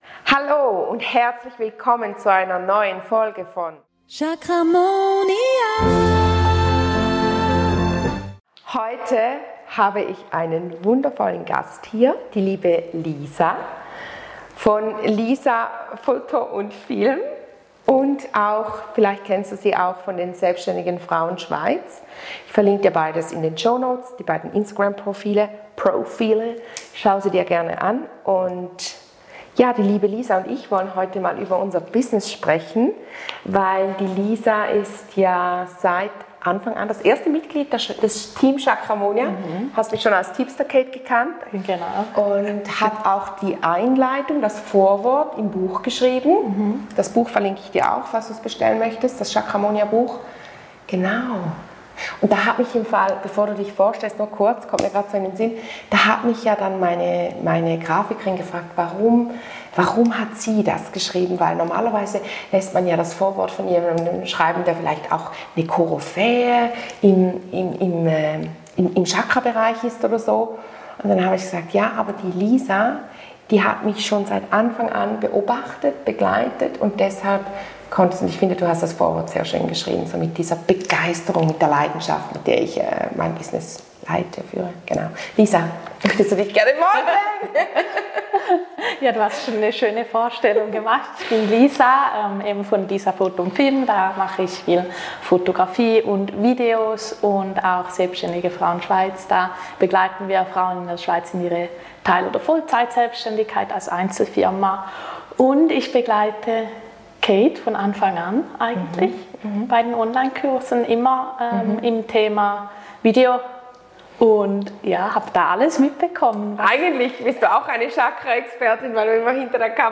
Wir haben einen super spannenden Austausch darüber geführt, wie Instagram heute unser Leben und unser Selbstbild prägt – und welche Tipps sie hat, um das Beste daraus zu machen.